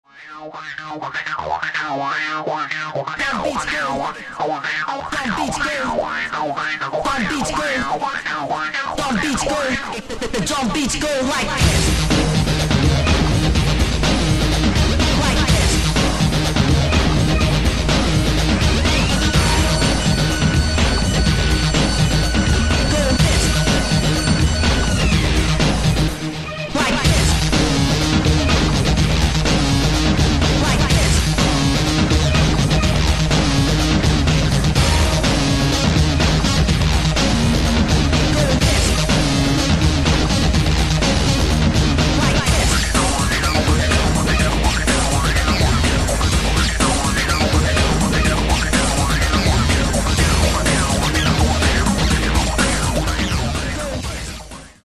"The Prodigy", a hard techno style with breakbeat
The tracks needed to feel aggressive